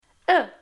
[ø].mp3